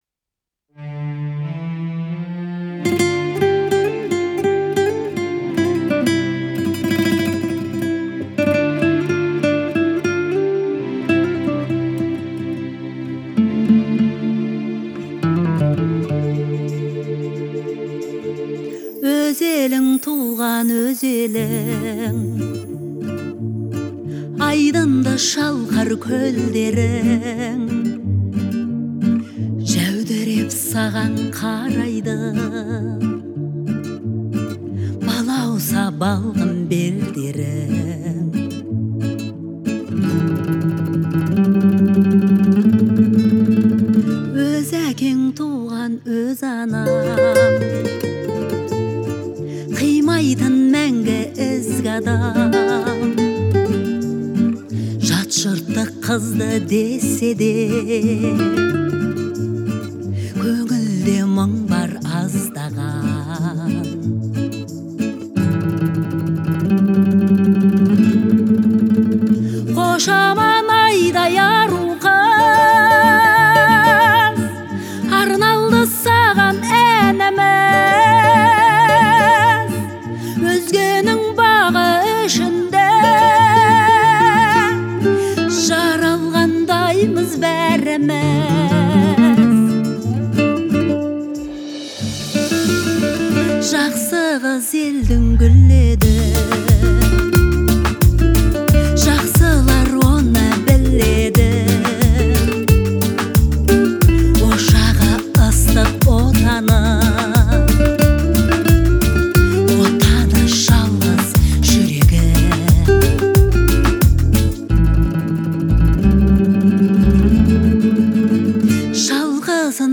используя традиционные инструменты и выразительный вокал.